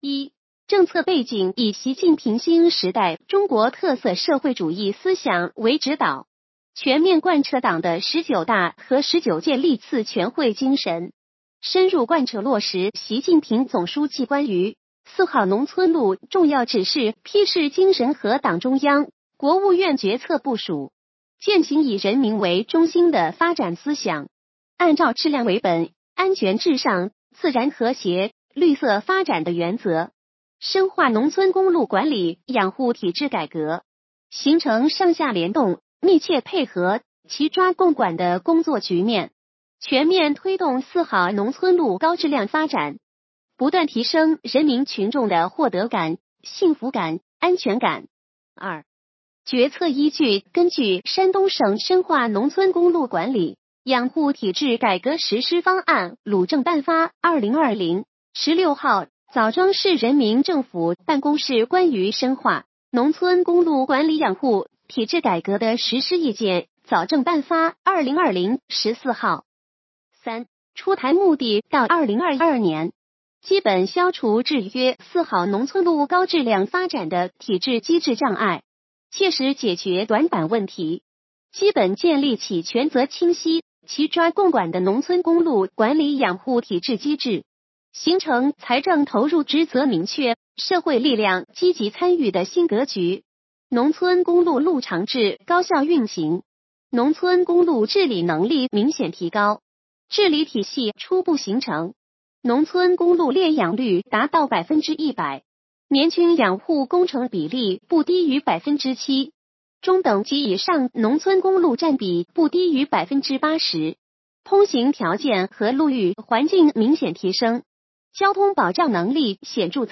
语音解读：山亭区人民政府办公室关于印发山亭区深化农村公路管理养护体制改革实施方案的通知